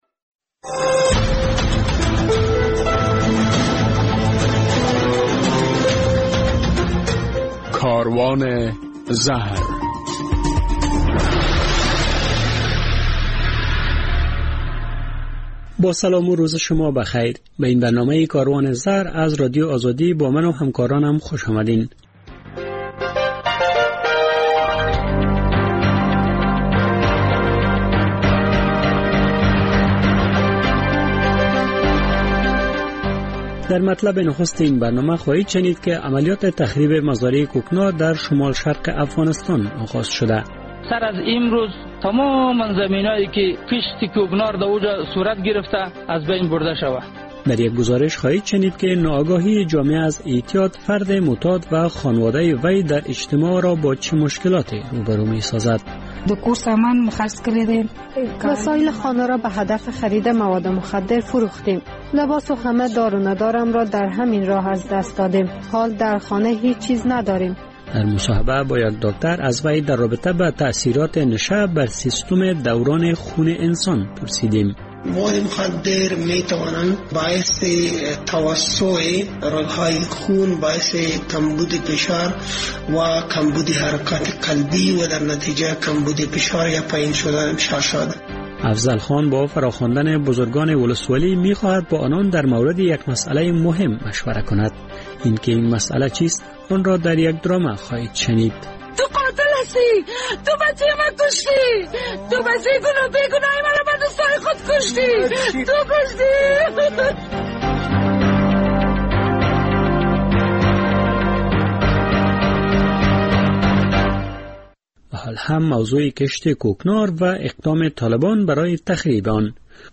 در مصاحبه با یک متخصص از وی پرسیدیم که نشه چگونه سیستم دوران خون انسان را...